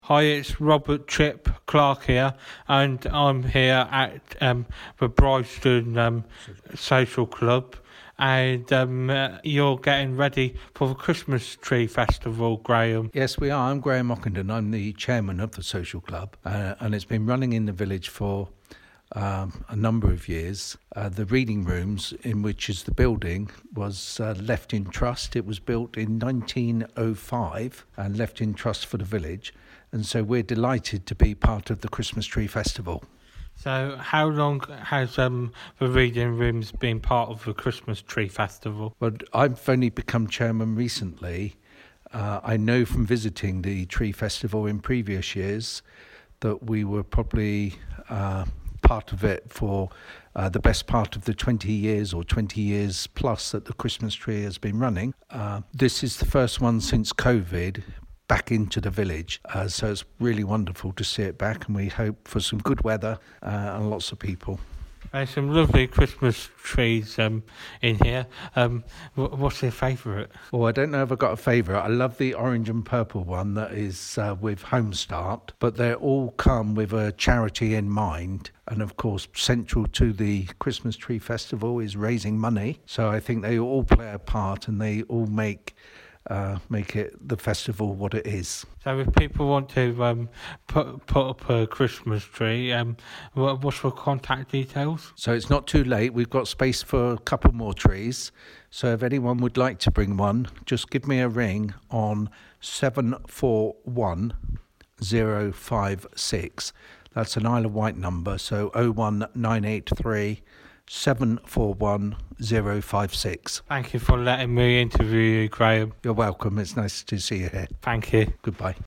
Brighstone Christmas Tree Festival 2023